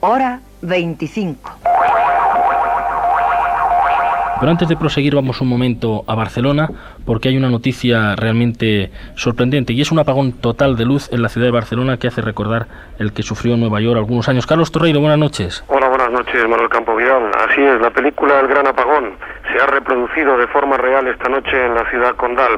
Indicatiu del programa, informació d'una apagada elèctrica general a la ciutat de Barcelona.
Informació des de la central de la Guàrdia Urbana de Barcelona.
Informatiu